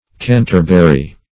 Canterbury \Can"ter*bur*y\ (k[a^]n"t[~e]r*b[e^]r*r[y^]), prop.